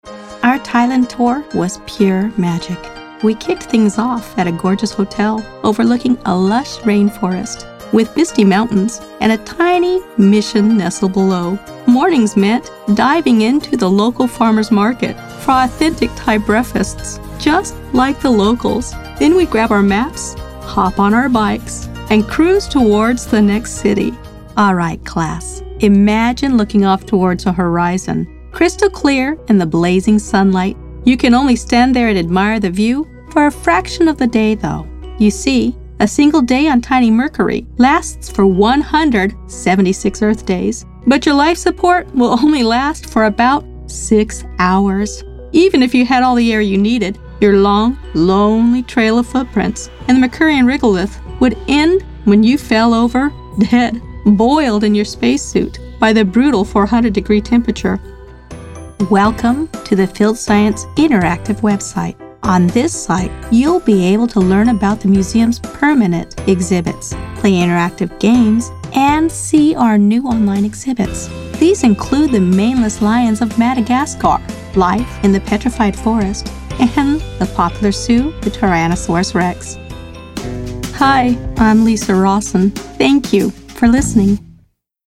My narration style is thoughtful and immersive, often described as grounding and familiar, helping listeners feel at home within the world of a story.
Narrative Demo
narrative-demo.mp3